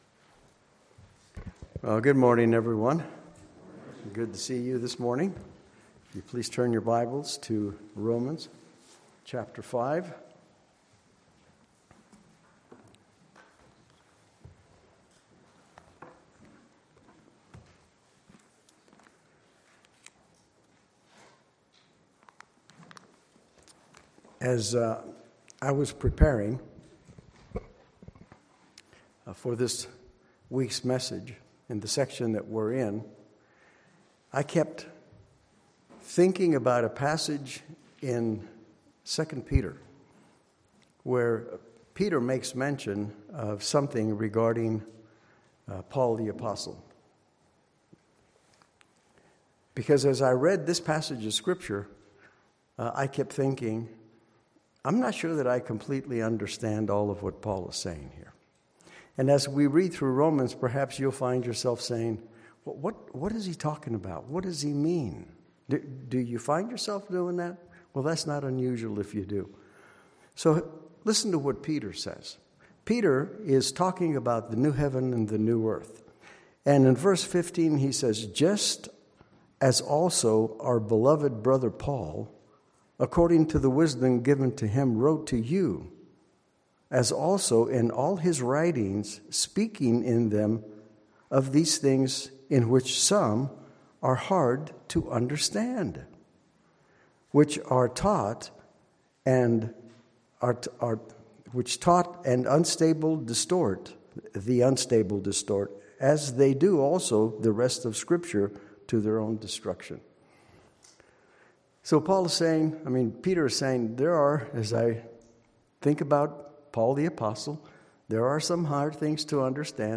Passage: Romans 5:12-17 Service Type: Sunday Morning Worship « God’s Incomparable Love Where Sin Abounded